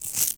grass8.ogg